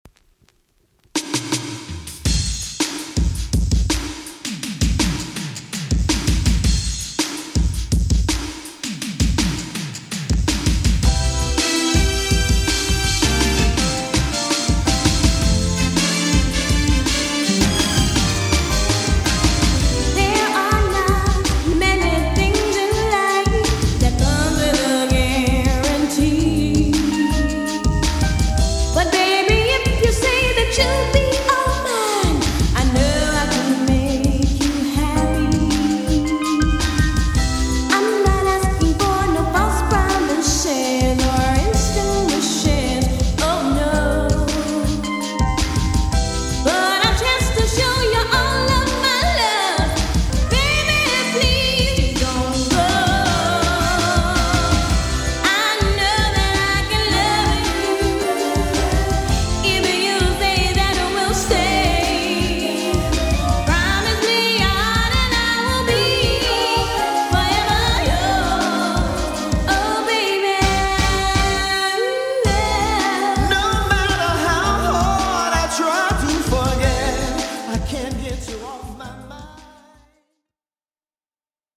R&B／ソウル・シンガー
男性ヴォーカルを交え歌い上げるミディアムテンポの弾むラヴ・ソング